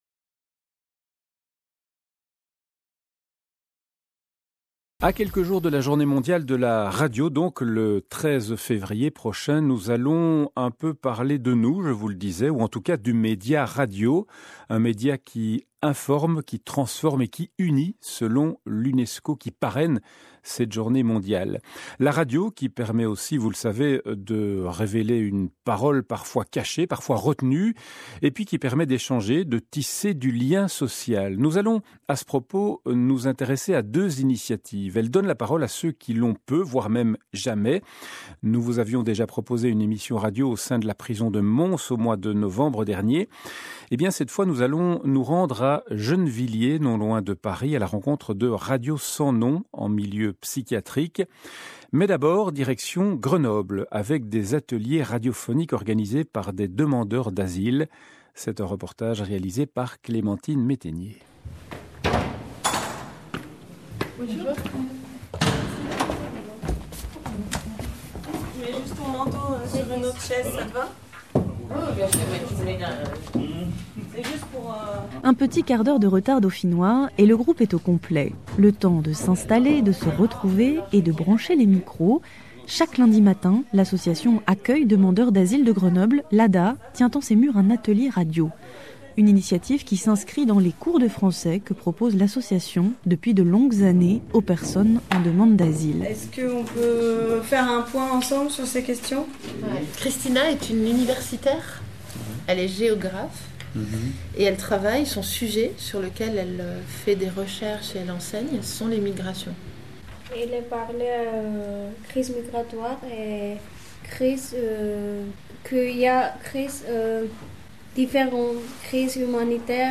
Reportage-Ateliers-radio-RTBF.mp3